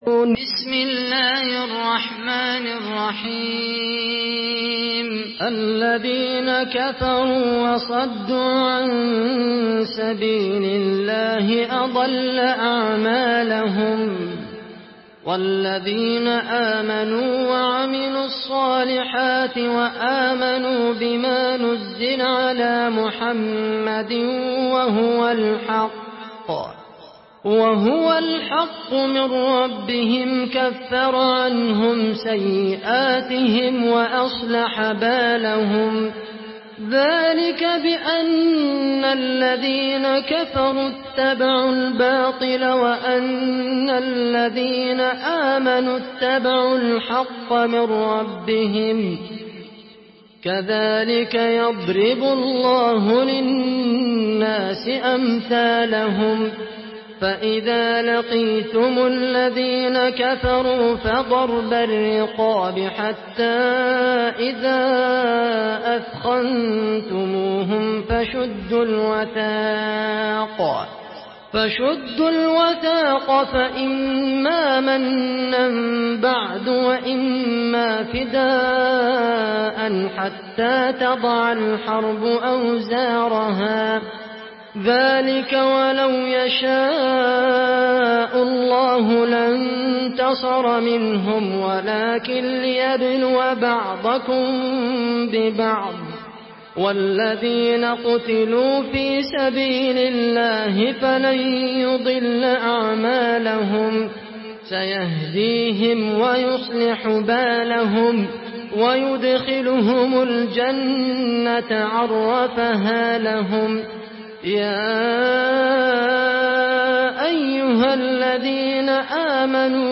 سورة محمد MP3 بصوت خالد القحطاني برواية حفص
مرتل حفص عن عاصم